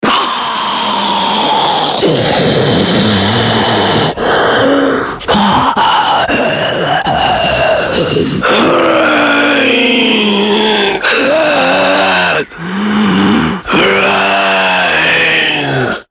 spoty radio
Zombie_teaser_MSTR.mp3